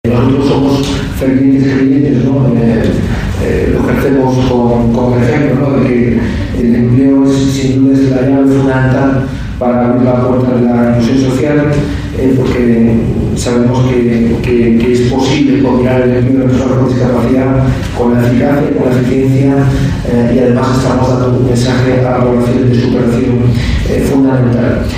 Embajadores, cónsules y consejeros de embajadas participaron en la sede ‘Por Talento Digital’, de Fundación ONCE, en el VII Encuentro Diplomacia para la Inclusión organizado por el Grupo Social ONCE y la Academia de la Diplomacia, bajo el patrocinio del embajador de la República Checa en nuestro país, que ostenta la presidencia semestral del Consejo de la UE.